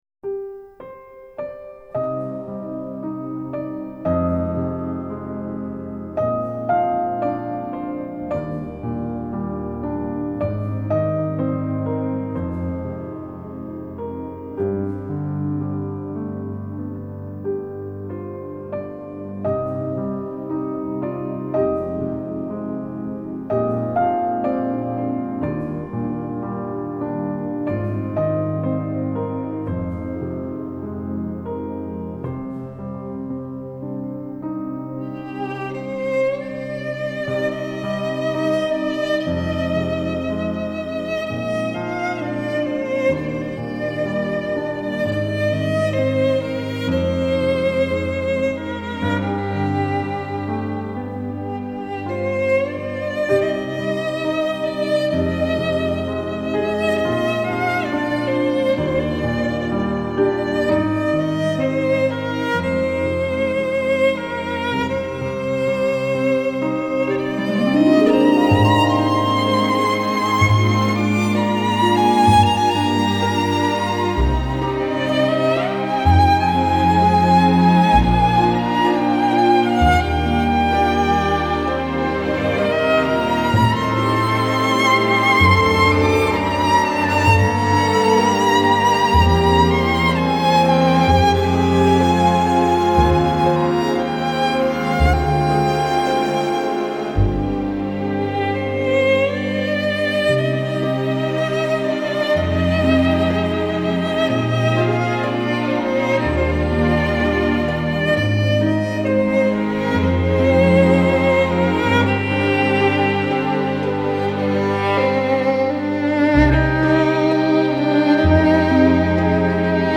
Celtic Mix